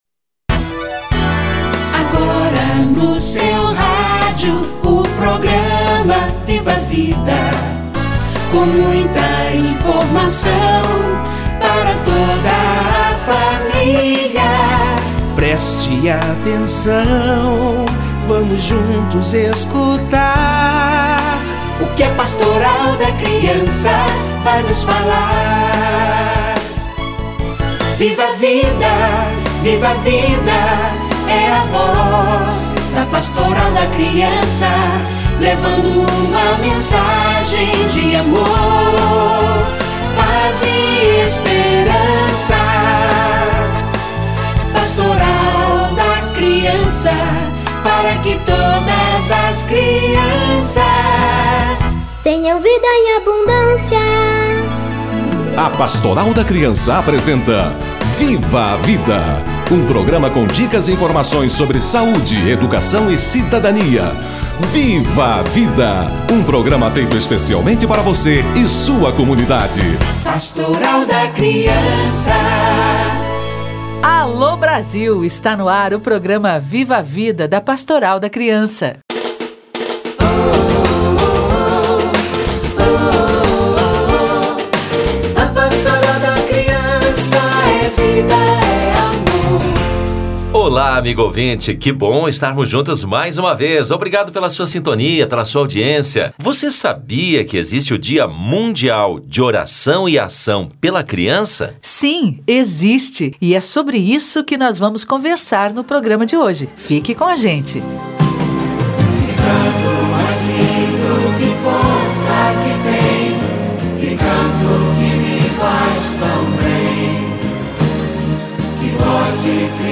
Dia de oração e ação pela criança - Entrevista